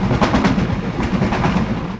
기차소리.mp3
기차가 다가오고
지나가고
멀어지는 소리는